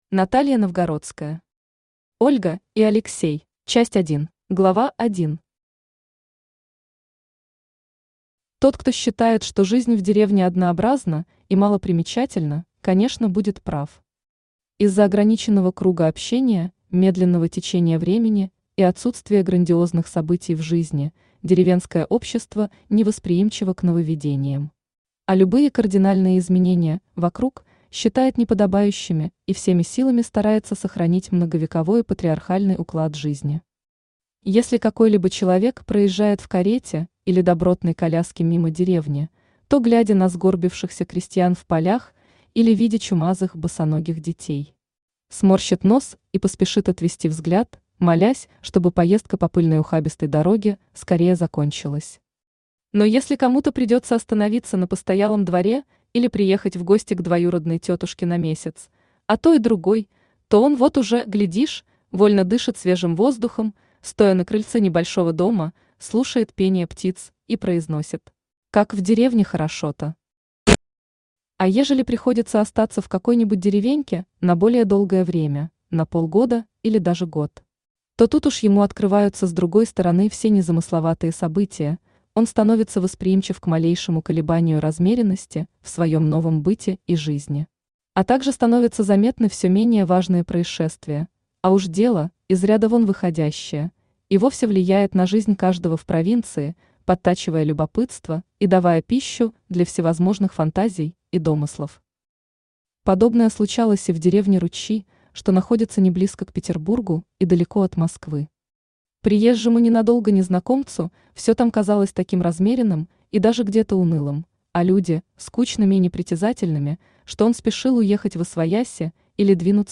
Аудиокнига Ольга и Алексей | Библиотека аудиокниг
Aудиокнига Ольга и Алексей Автор Наталья Новгородская Читает аудиокнигу Авточтец ЛитРес.